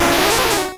Cri de Kokiyas dans Pokémon Rouge et Bleu.